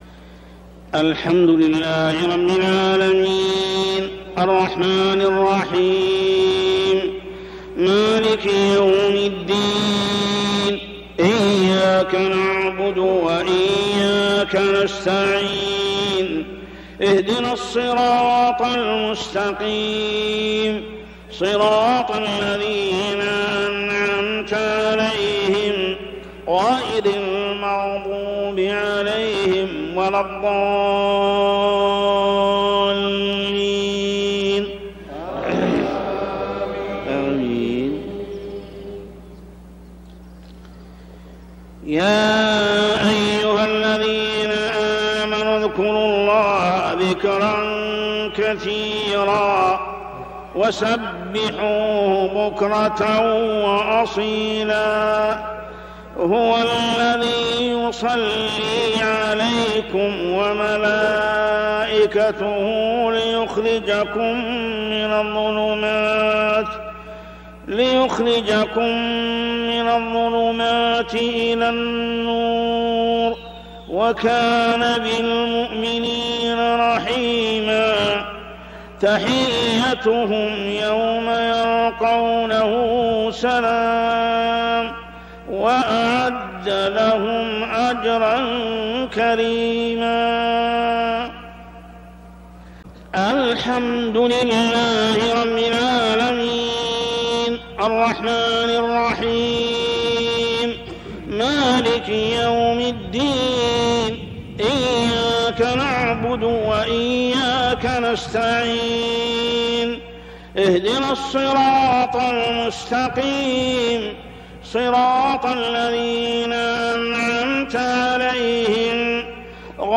صلاة العشاء من عام 1421هـ سورة الأحزاب 41-48 | Isha prayer Surah Al-Ahzab > 1421 🕋 > الفروض - تلاوات الحرمين